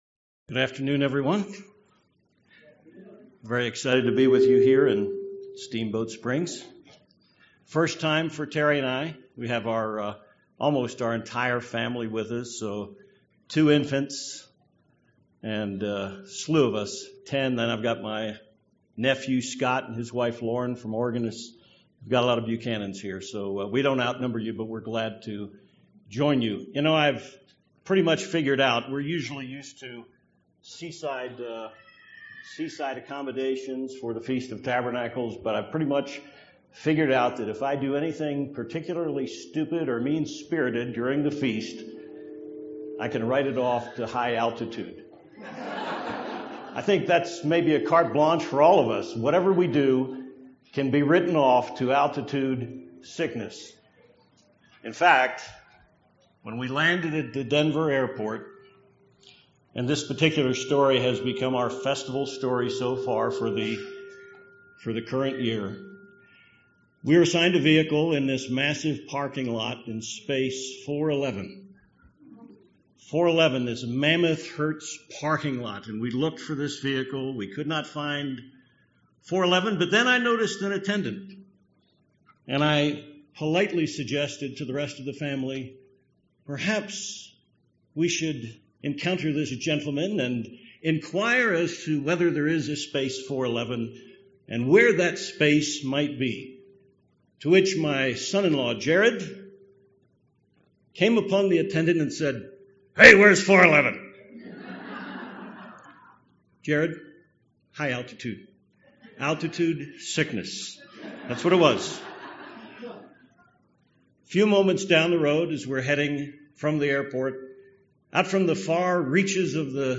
This sermon was given at the Steamboat Springs, Colorado 2018 Feast site.